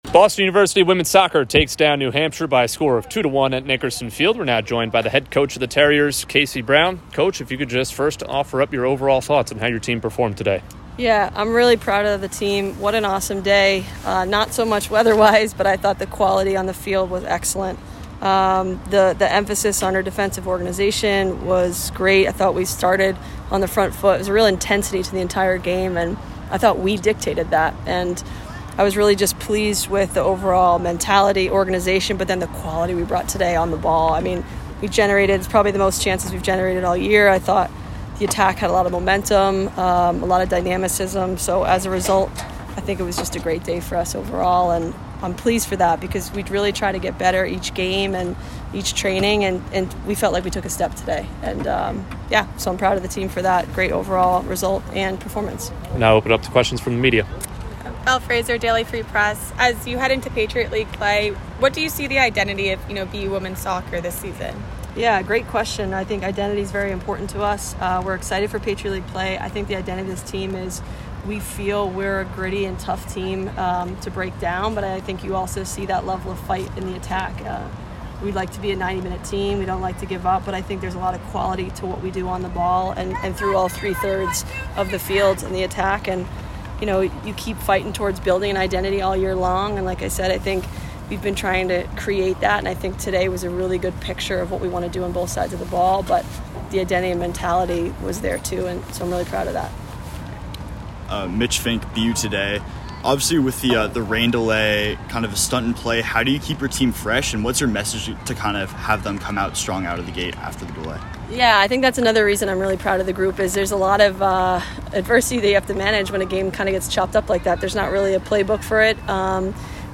Women's Soccer / UNH Postgame Interviews (9-10-23)